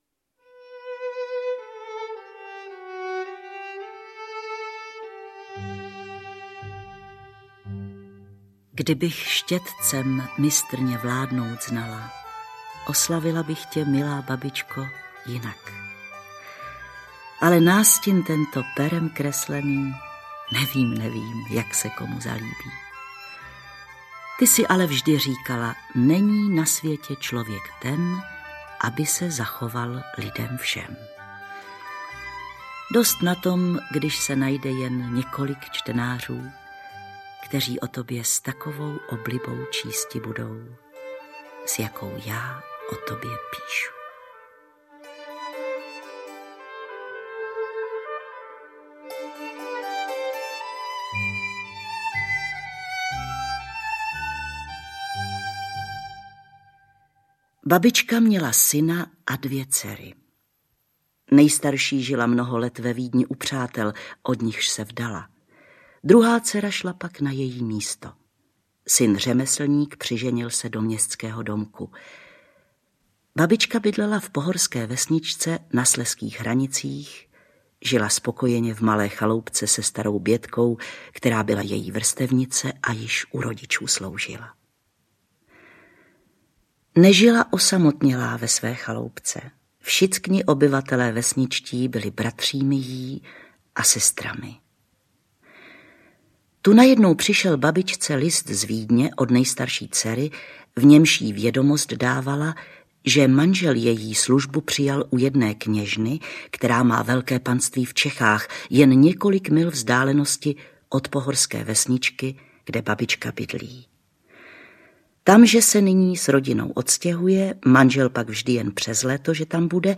Babička audiokniha
Čte Hana Kofránková.
Ukázka z knihy